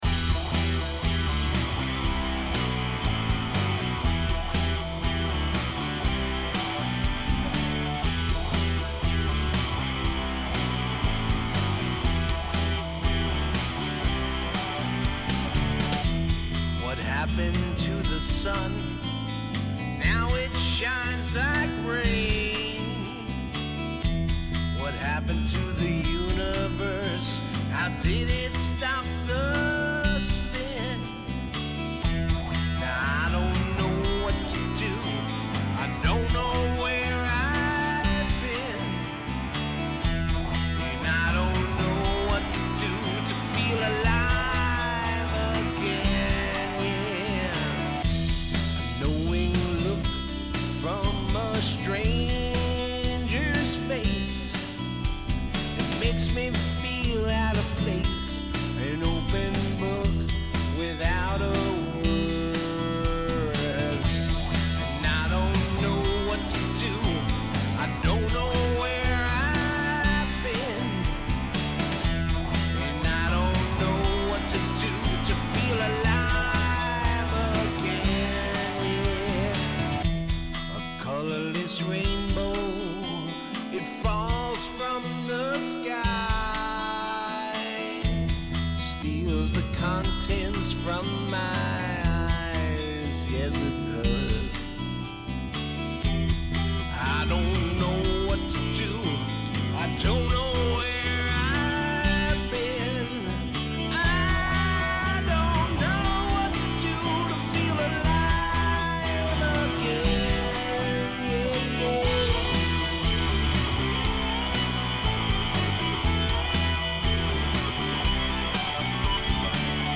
Drums, Guitar and Bass